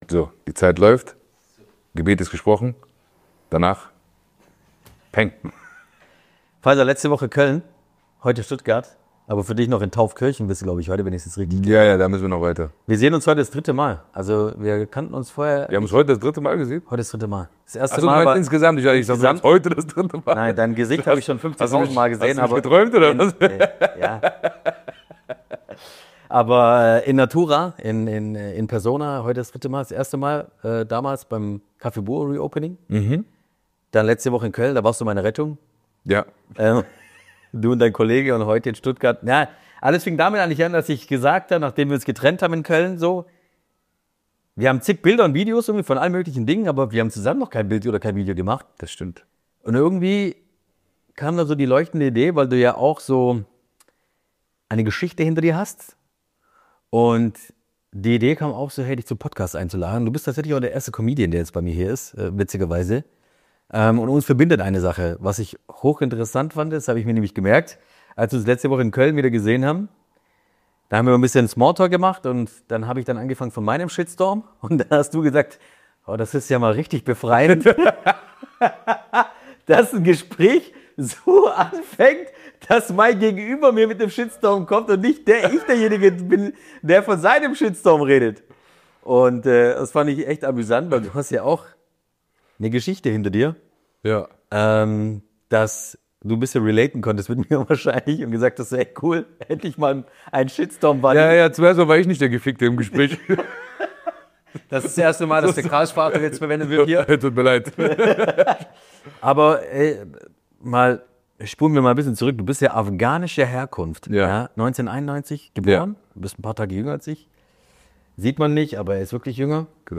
Ein Gespräch über Cancel Culture, Widerstandskraft und den Glauben, auch im größten Chaos nicht aufzugeben.